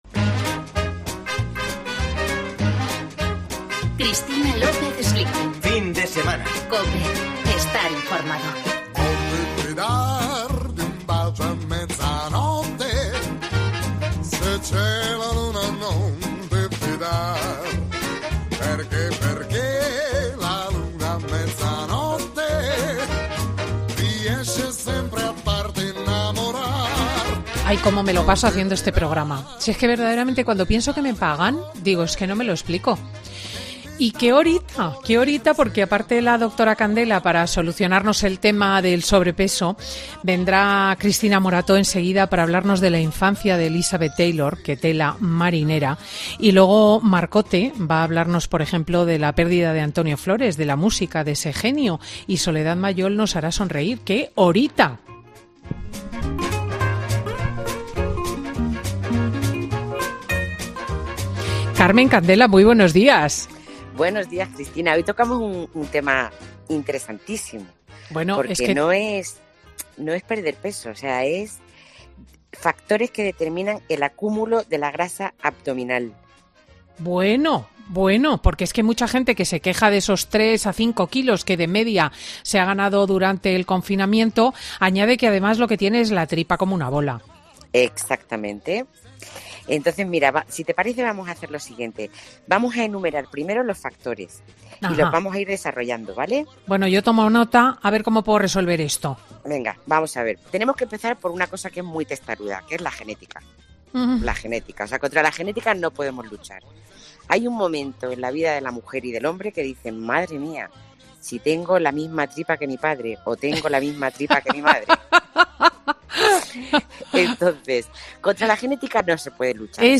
La endocrinóloga habla sobre el peso que hemos cogido en el confinamiento y de cómo perderlo